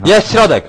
Worms speechbanks
drop.wav